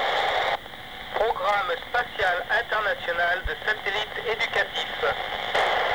Recorders was done by me on 15/11/98 23:04 utc with FT 203R hand-RTX , antenna Jpole homemade.